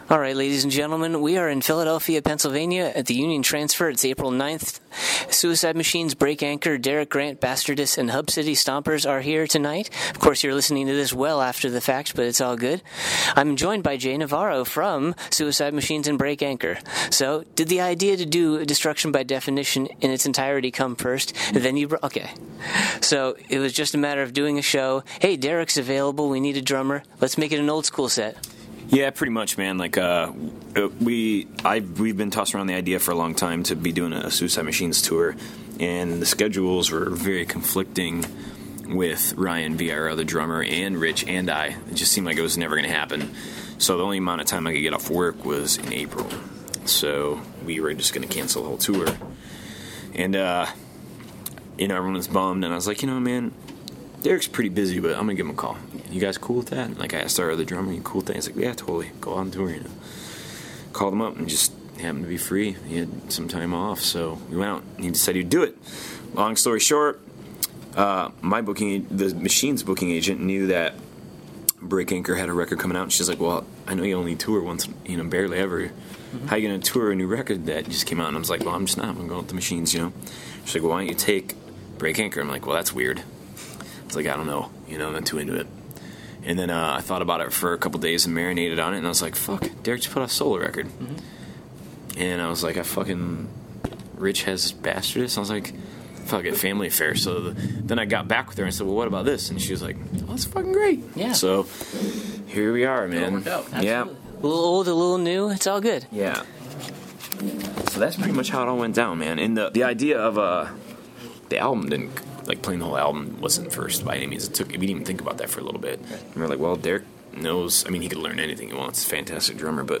56-interview-the-suicide-machines.mp3